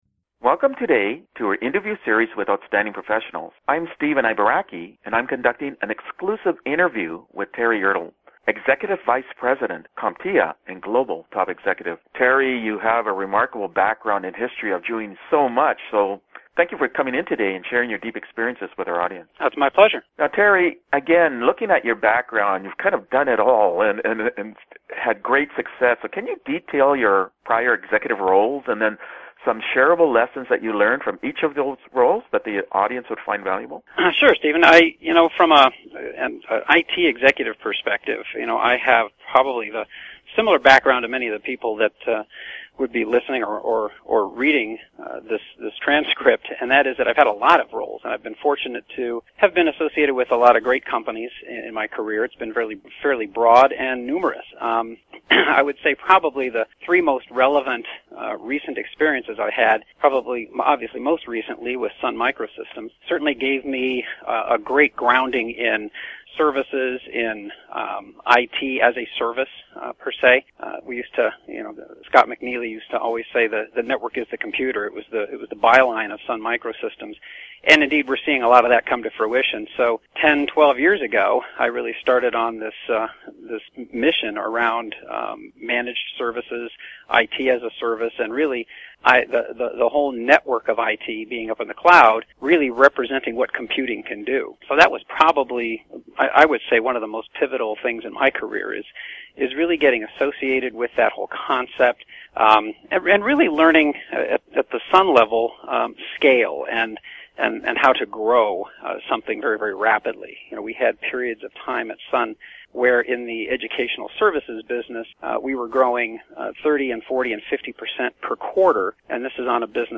Interview Time Index (MM:SS) and Topic